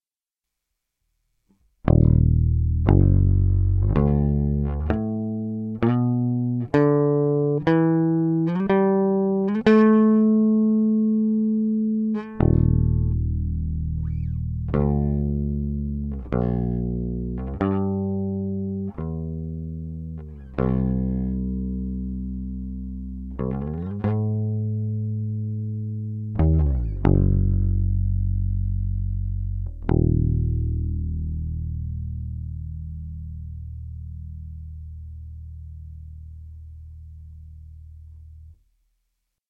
需要あるのかわかりませんがiO2とUR28Mとで適当に弾いたプレベの素の録音サンプルおいときますね。
bass_UR28M.mp3